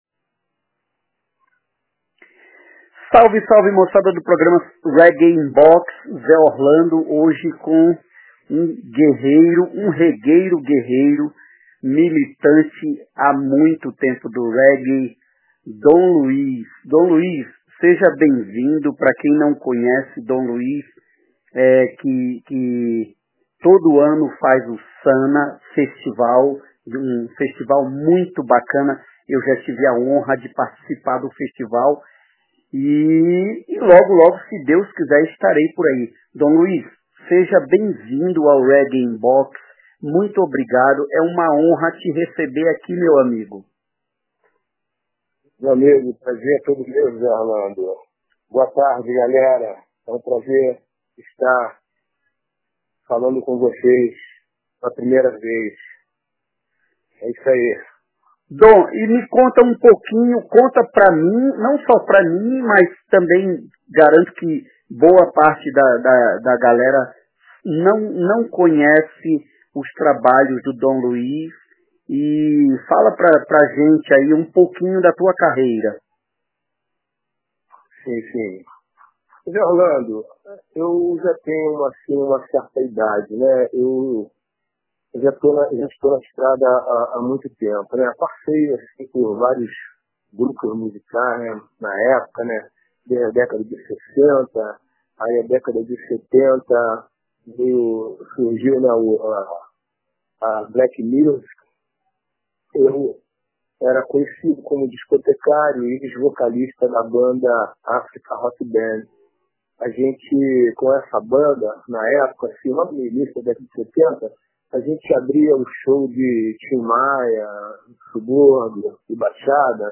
RIB Entrevista